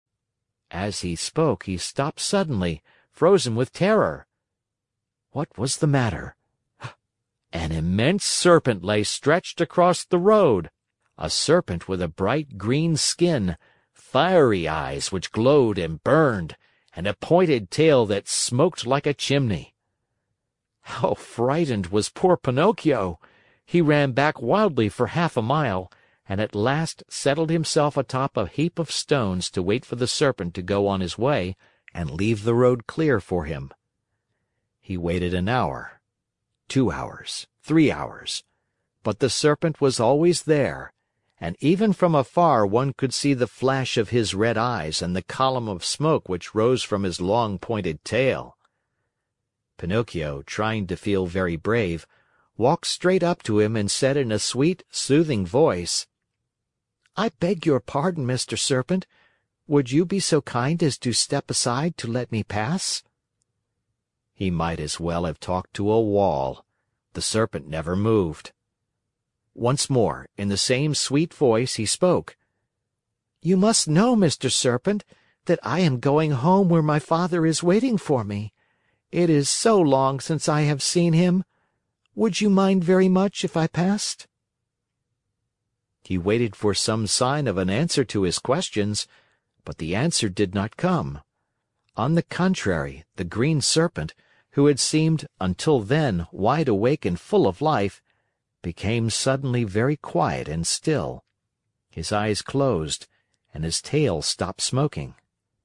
在线英语听力室木偶奇遇记 第60期:匹诺曹重获自由(2)的听力文件下载,《木偶奇遇记》是双语童话故事的有声读物，包含中英字幕以及英语听力MP3,是听故事学英语的极好素材。